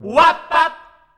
WAB BAB.wav